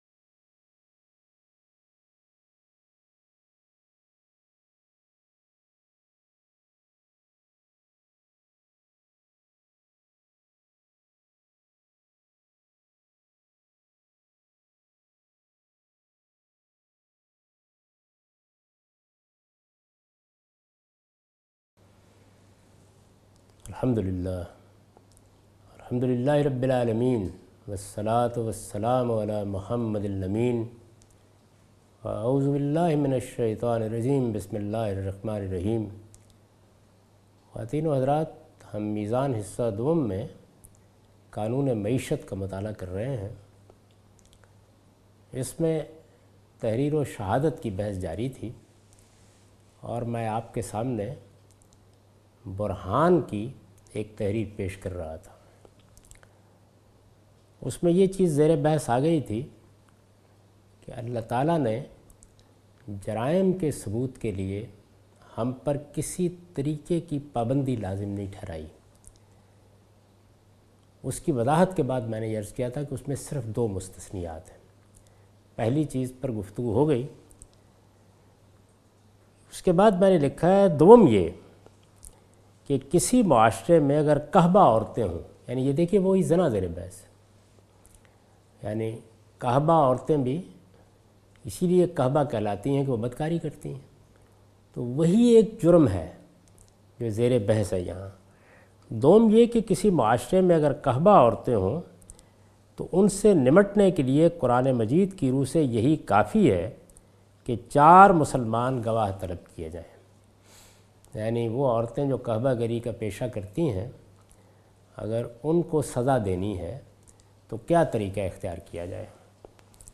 Meezan Lecture 'Meezan Class— "Economic Directive". Javed Ahmad Ghamidi teaching from his book Meezan. In this lecture he teaches from the chapter "Qanoon e Maeshat".